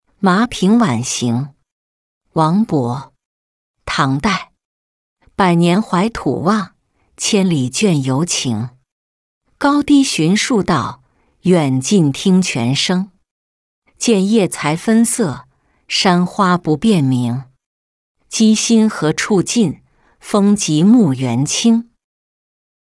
麻平晚行-音频朗读